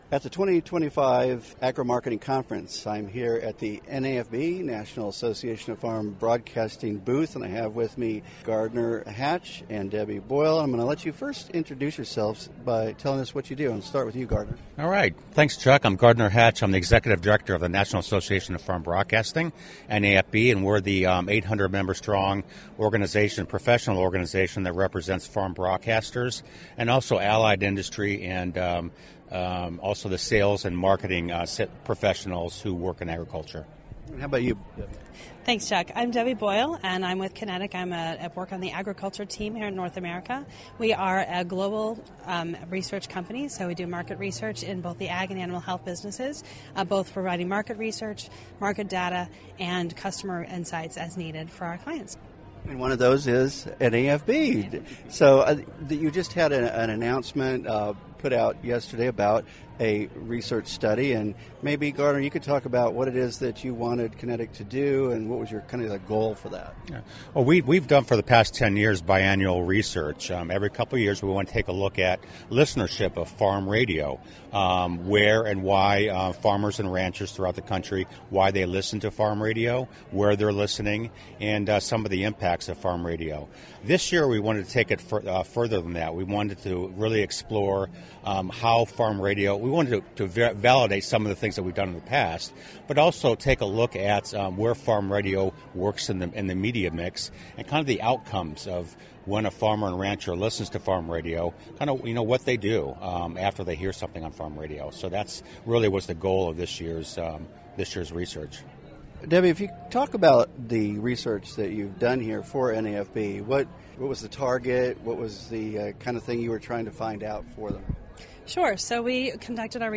Interview with AgWired